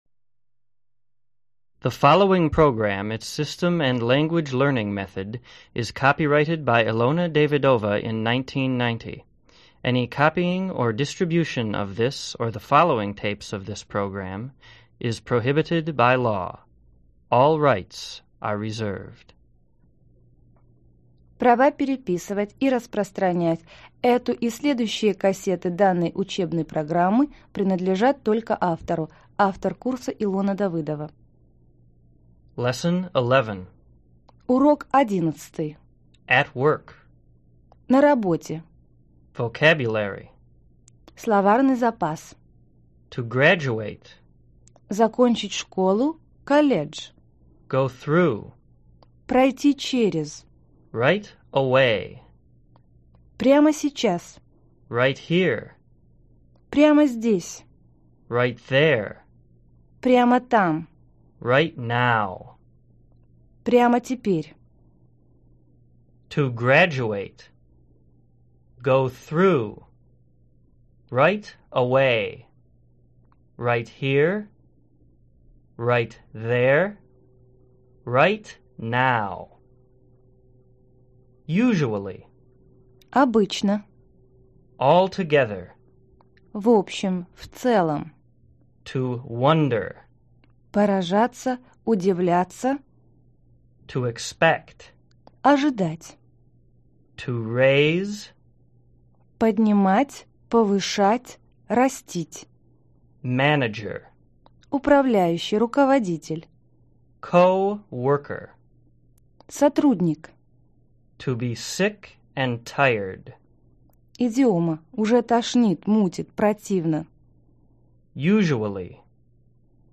Аудиокнига Разговорно-бытовой английский. Диск 11: Работа | Библиотека аудиокниг